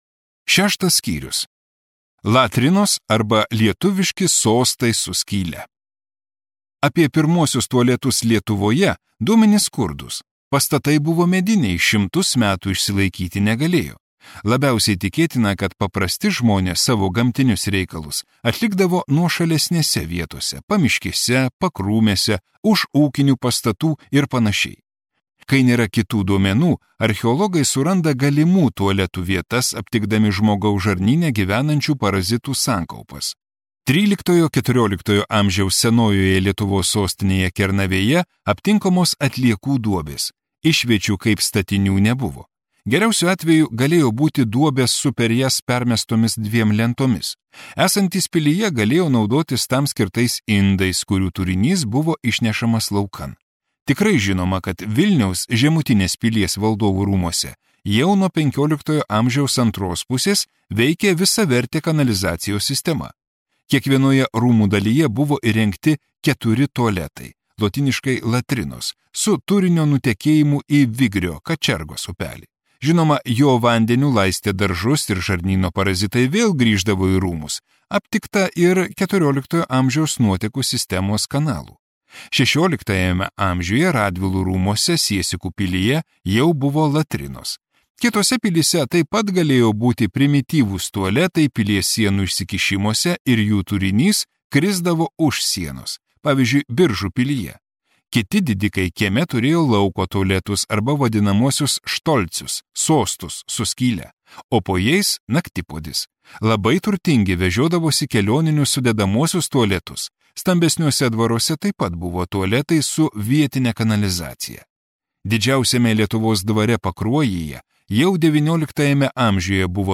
Audio knyga